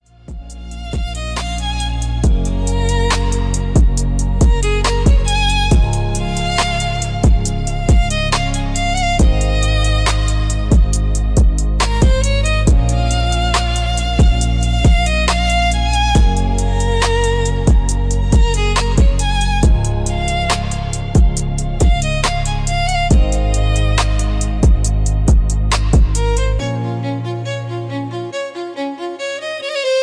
classical crossover instrumental music